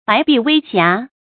白璧微瑕 bái bì wēi xiá 成语解释 璧：中间有孔的扁圆形玉器；瑕：玉上的斑点。洁白的玉上的小斑点。比喻美好的人或事物的不足之处；美中不足。
成语简拼 bbwx 成语注音 ㄅㄞˊ ㄅㄧˋ ㄨㄟ ㄒㄧㄚˊ 常用程度 常用成语 感情色彩 中性成语 成语用法 主谓式；作主语、宾语、分句；表示缺点不足掩盖优点，也表惋 成语结构 主谓式成语 产生年代 古代成语 成语正音 瑕，不能读作“jiǎ”。